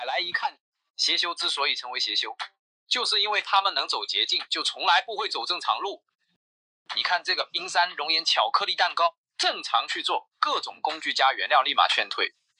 La voix de narration ultime pour blogueur culinaire
Voix off de recette
Synthèse vocale IA
Cadence naturelle et intonation expressive adaptées au contenu pédagogique.